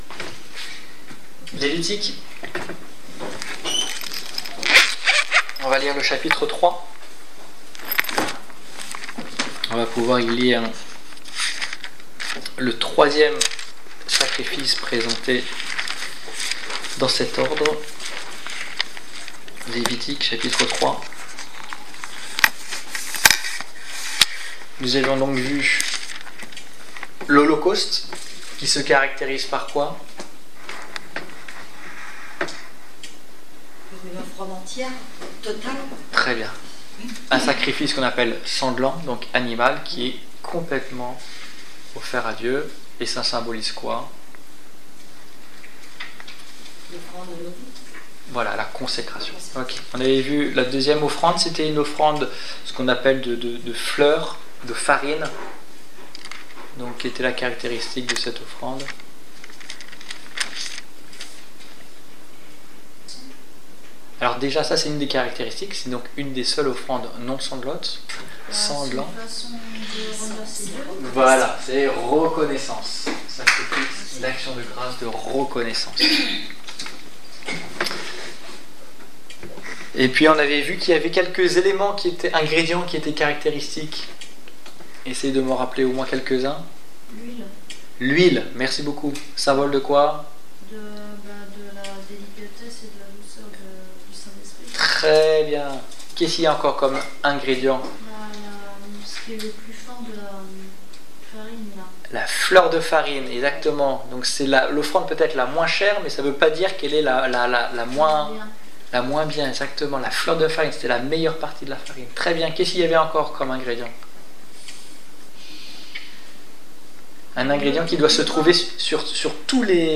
Étude biblique du 26 octobre 2016